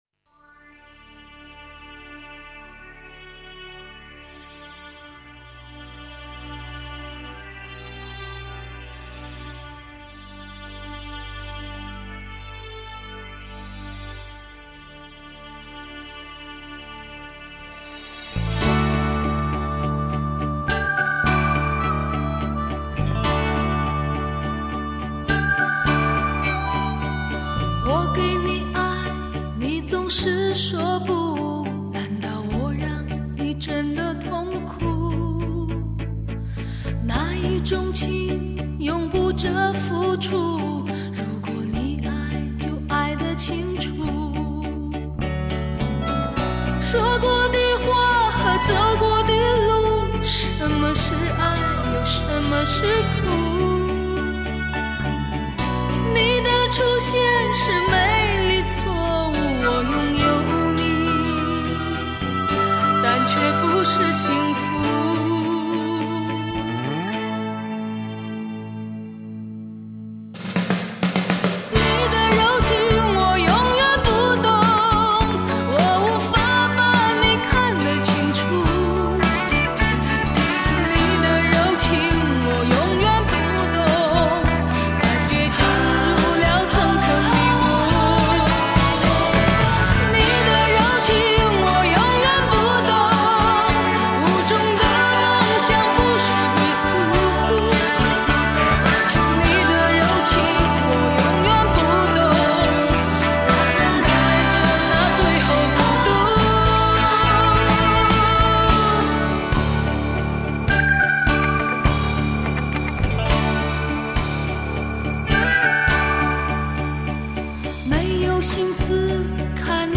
歌曲中流露出悲切情绪和淡淡的忧伤，一个柔弱的痴情女孩岂不让人爱怜。而歌曲所表现出强烈的乐队化风格使得音乐更具现场感染力。